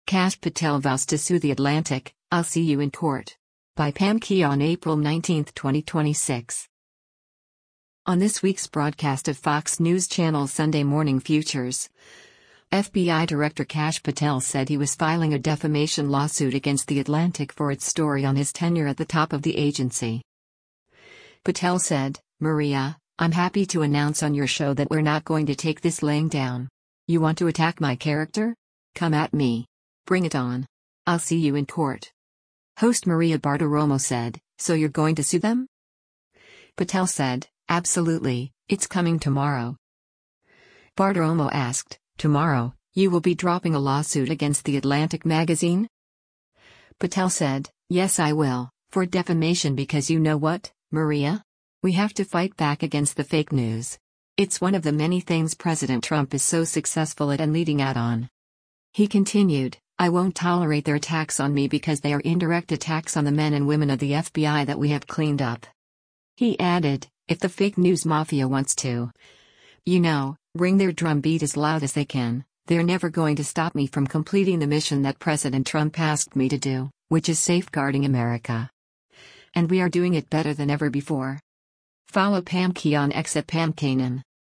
On this week’s broadcast of Fox News Channel’s “Sunday Morning Futures,” FBI Director Kash Patel said he was filing a defamation lawsuit against The Atlantic for its story on his tenure at the top of the agency.